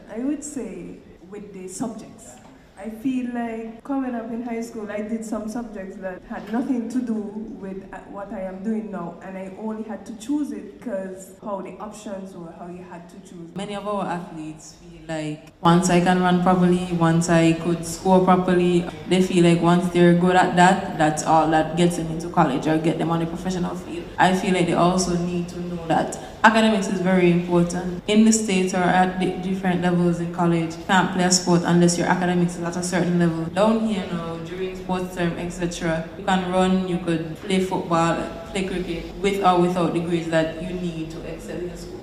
The Department of Sports recently held its Athletes Forum under the theme “Athletes engaging Athletes” on Wednesday July 30th at the Malcolm Guishard Recreational Park.